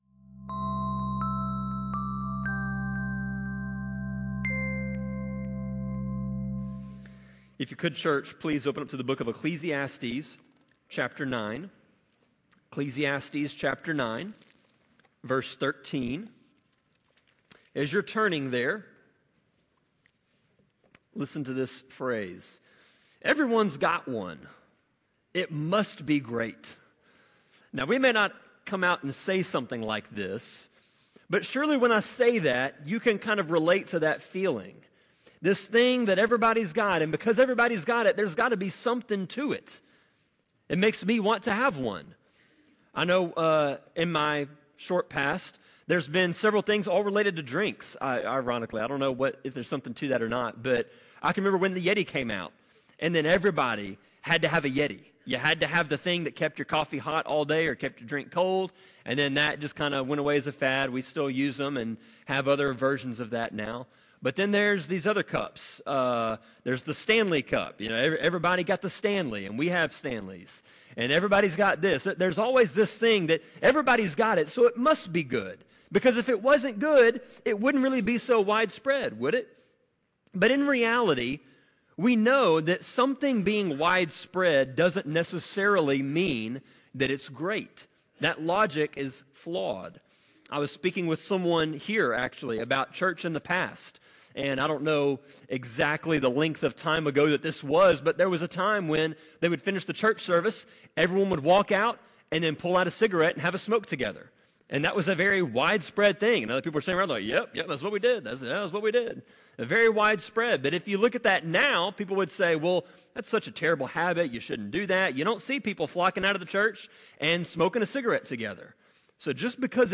Sermon-25.4.27-CD.mp3